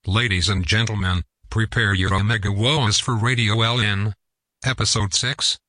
prepare your omegawoah (tts)